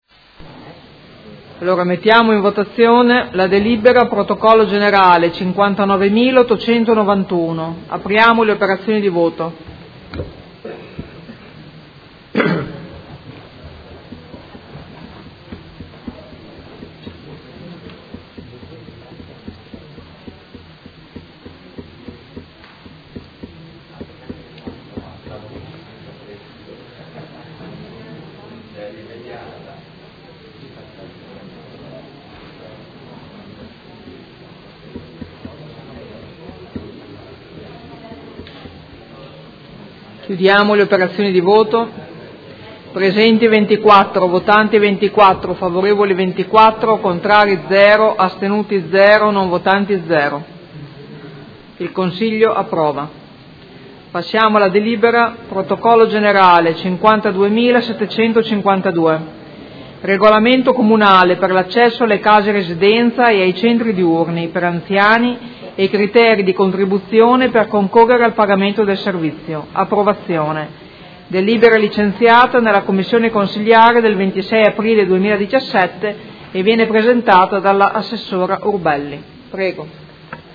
Presidentessa — Sito Audio Consiglio Comunale
Seduta dell'11/05/2017.